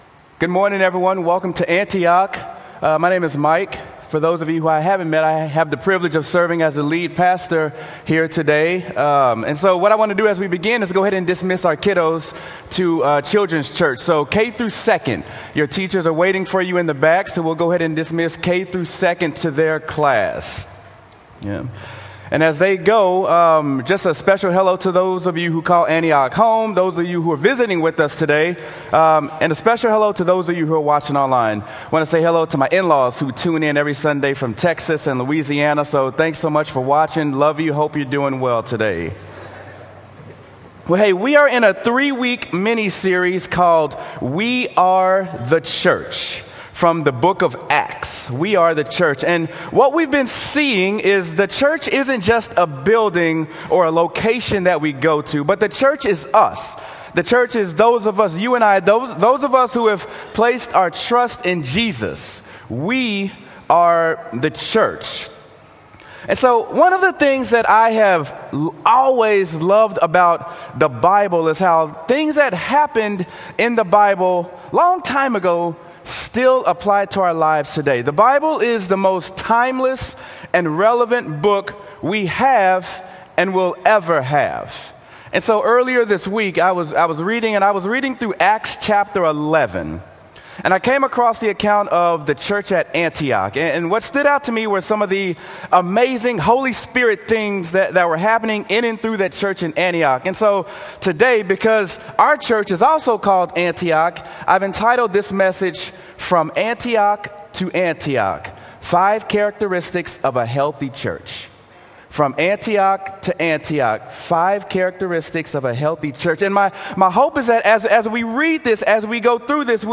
Sermon: Acts: We Are the Church: From Antioch to Antioch | Antioch Community Church - Minneapolis